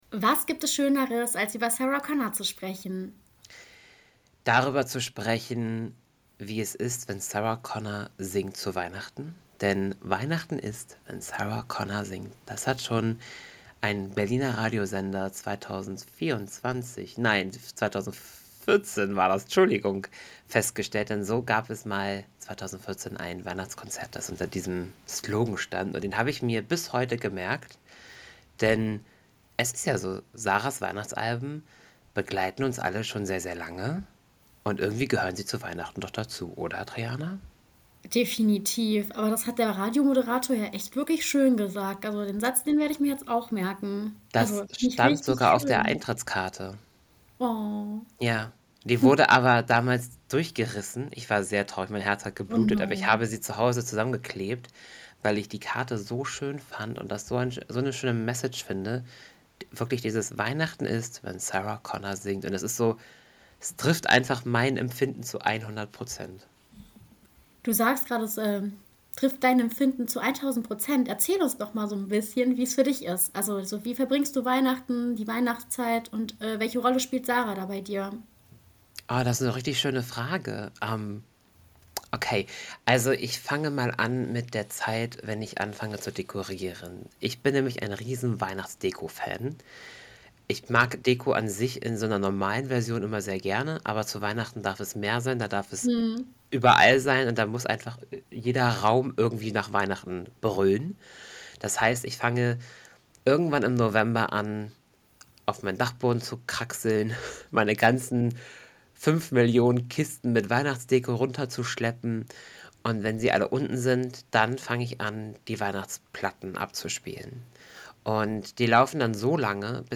Es fließen Tränen und es wird viel Musik gehört.